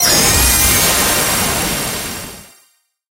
brawl_hypercharge_01.ogg